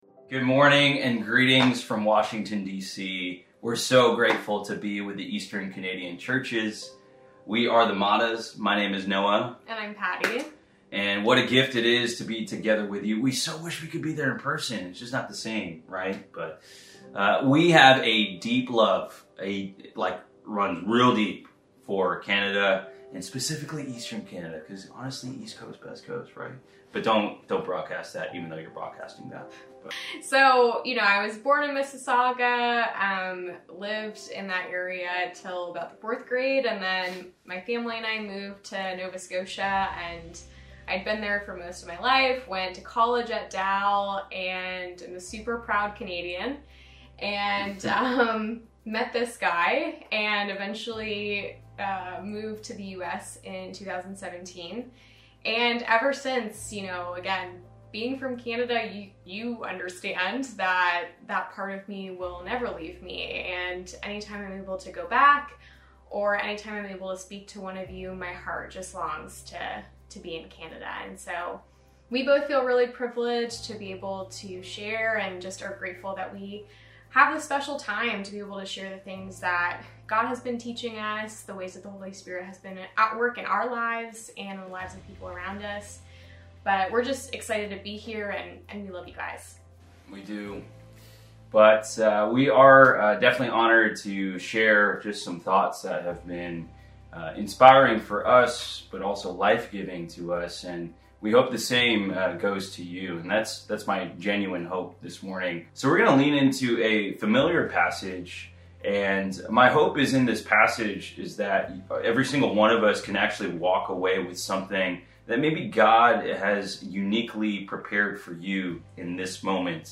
Long Live… The faith – NextGen special service
Be prepared to be inspired and challenged by speakers from the Next Generation presenting an engaging and relevant sharing on the “great faith” we must understand in this post-pandemic era!